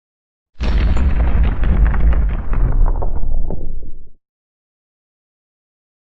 Explosion Low Fire Destruction Version 3